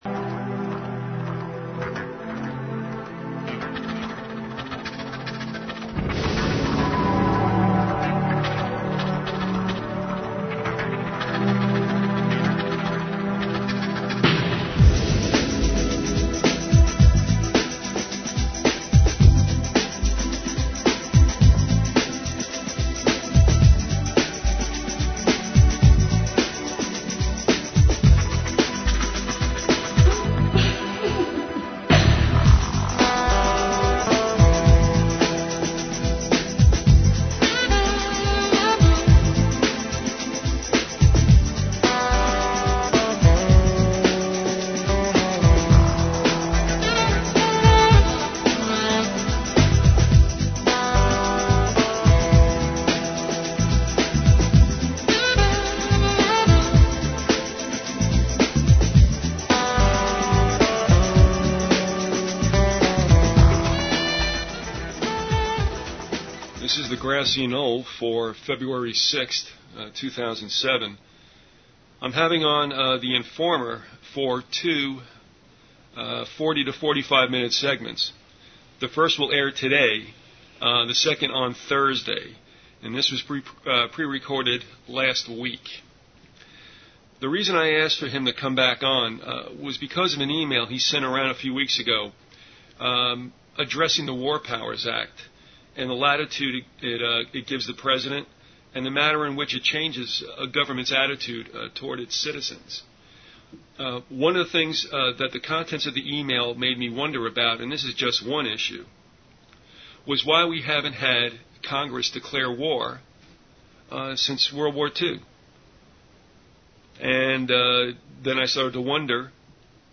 If you took notes and would like to share them with others, please send an email to the archivist and be sure to reference the title of the interview.